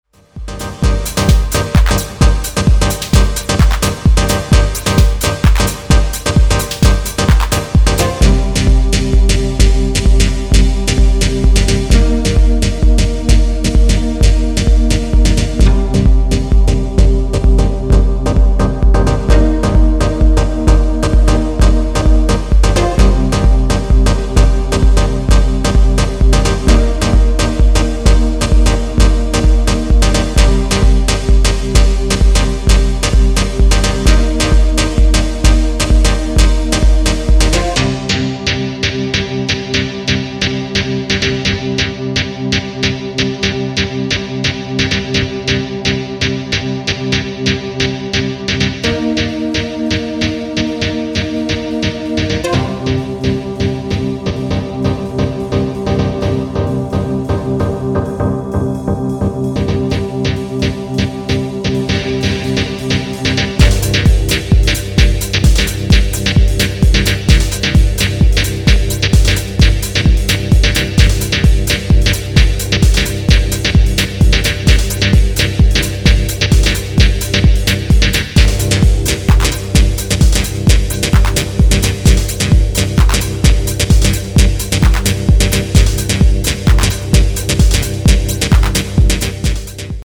Electro Electronix Techno